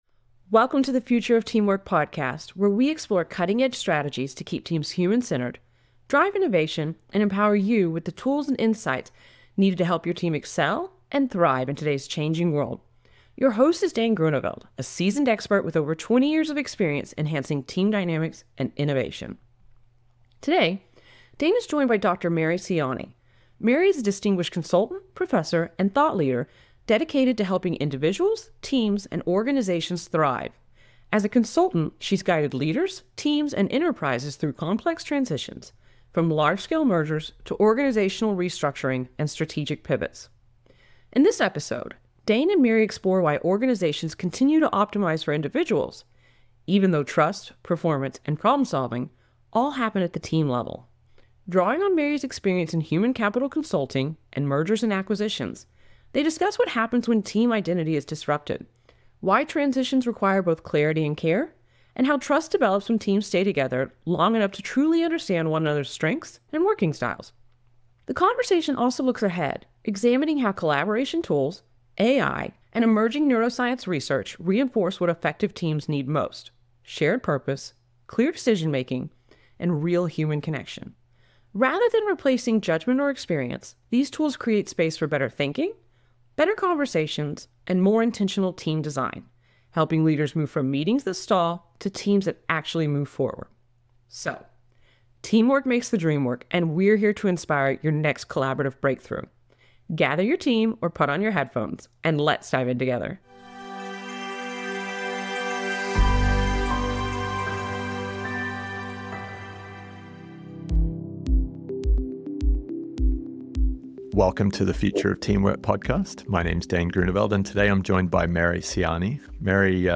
The conversation explores why teamwork